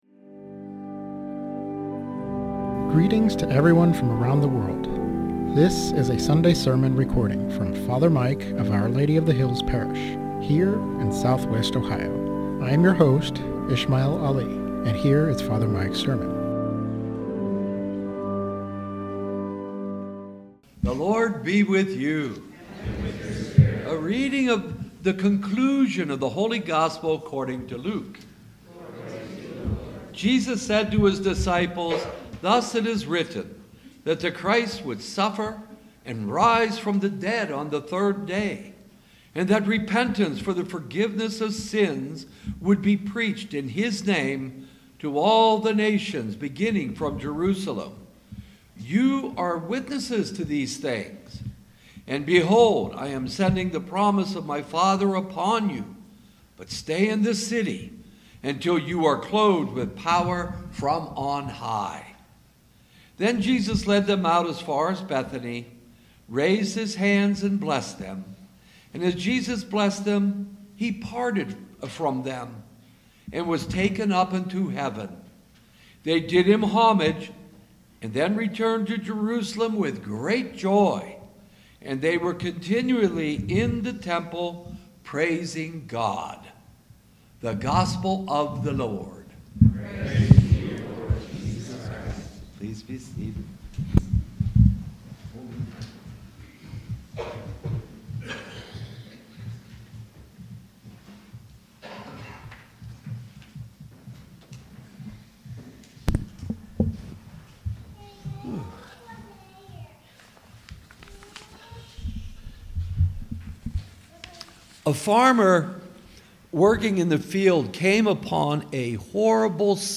Sermon on Ascension - Our Lady of the Hills - Church